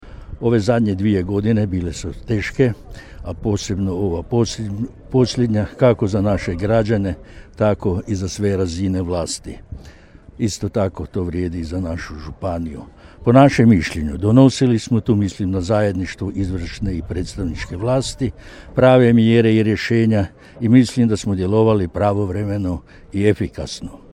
Svečana sjednica Skupštine Međimruske županije održana u atriju Staroga grada u Čakovcu bila je središnji događaj obilježavanja Dana Međimurske županije.
Svečana sjednica uz prigodne govore bila je prilika za pregled prethodine godine, ali i za dodjelu nagrada zaslužnim Međimurkama i Međimurcima.
Dragutin Glavina, predsjednik Skupštine Međimurske županije: